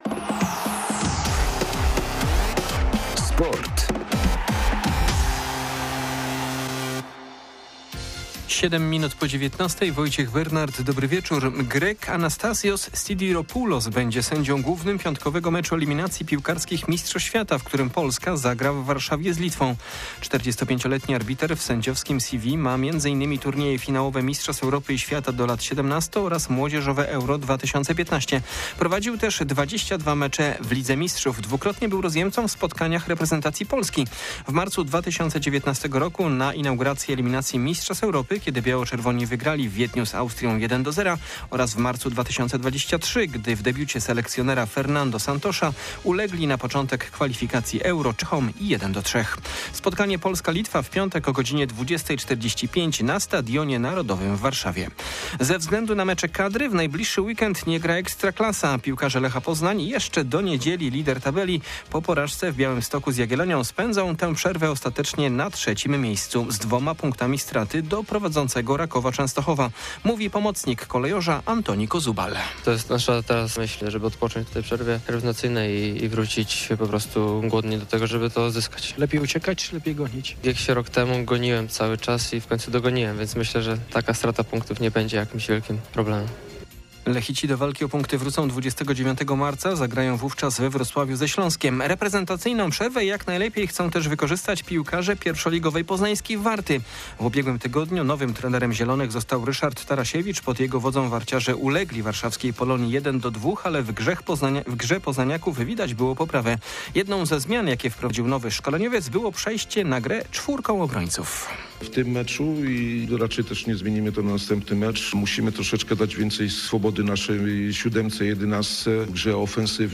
19.03.2025 SERWIS SPORTOWY GODZ. 19:05